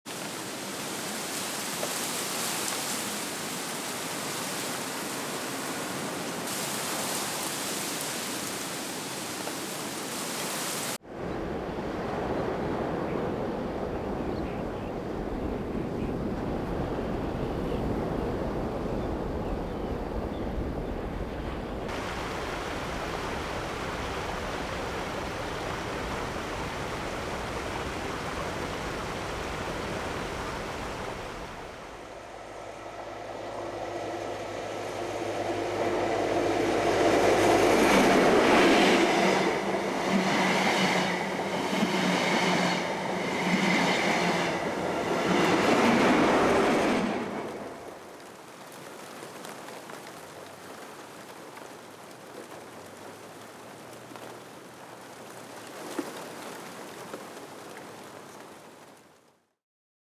Ambience
In this release you will find a series of atmospheric ambiences, both natural and industrial.
All samples were recorded at 96kHz 24 bit with a Zoom H5 and Sennheiser ME 67.
Ambience.mp3